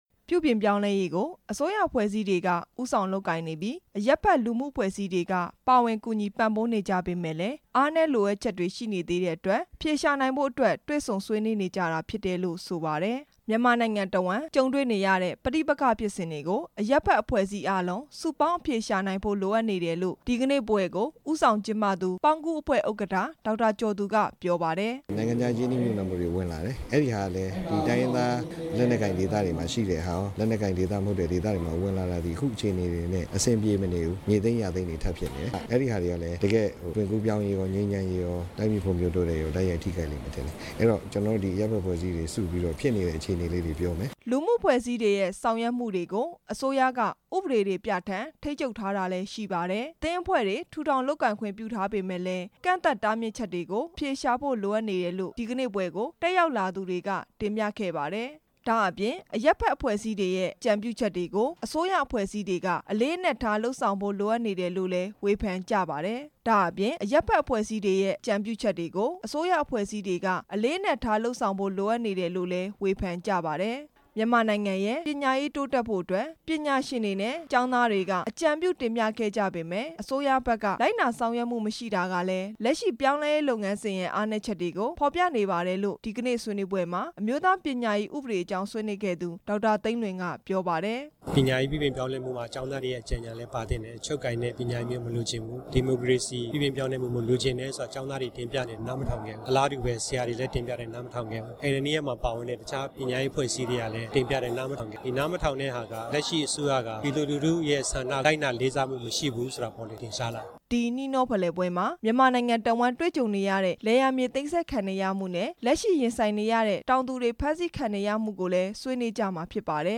အဲဒီနှီးနှောဖလှယ်ပွဲ အကြောင်း စုစည်းတင်ပြချက်